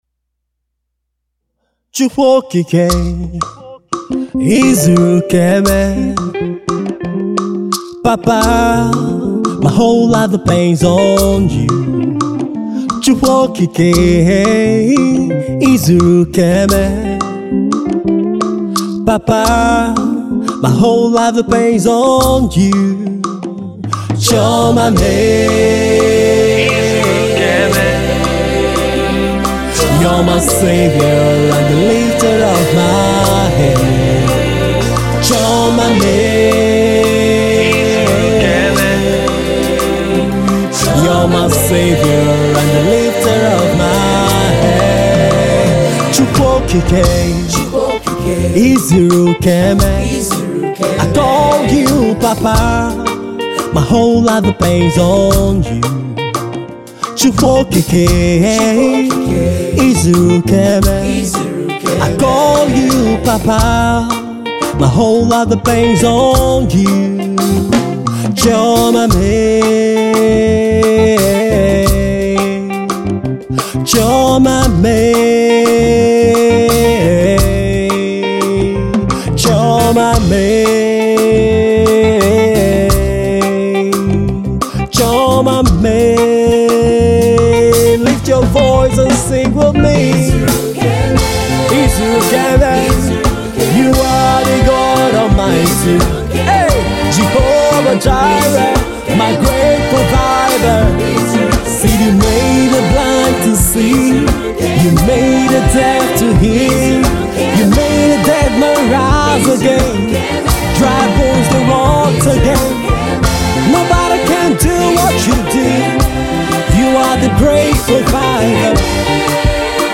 W.R.A.P stands for Worship Rap And Praise